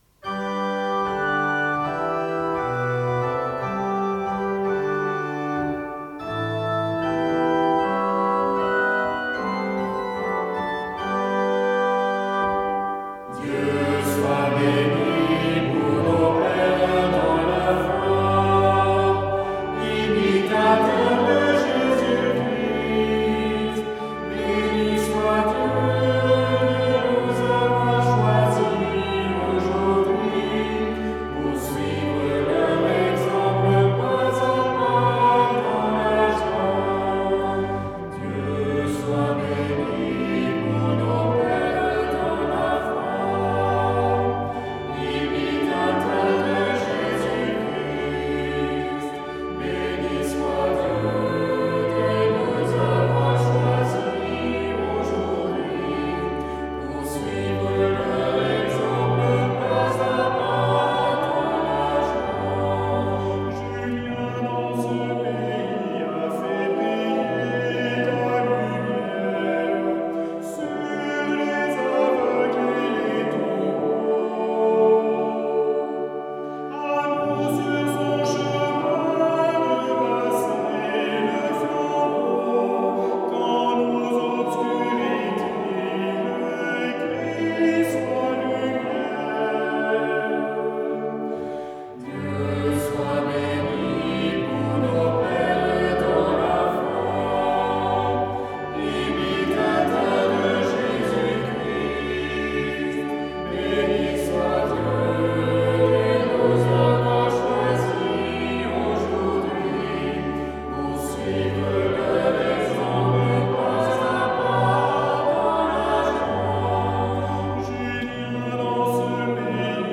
Cantique à Saint Julien de Berthier